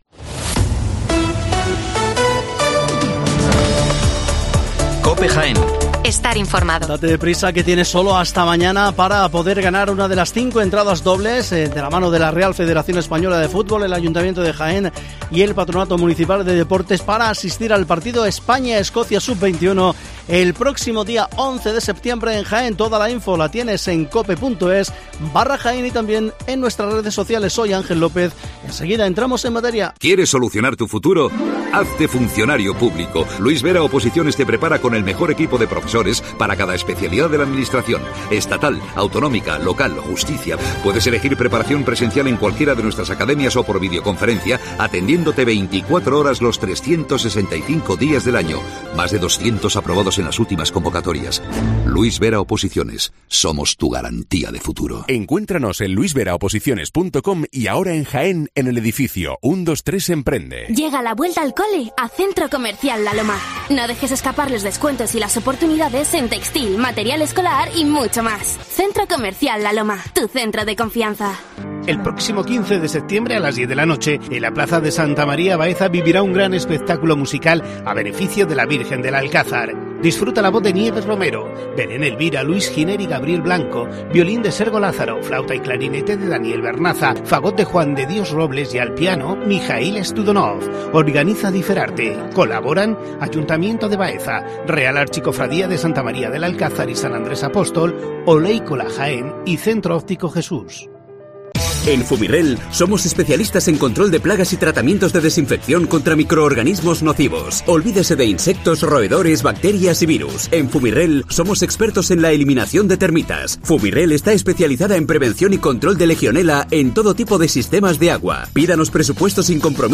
Charlamos con Santi Rodríguez del Festival Benéfico Santi y sus amigos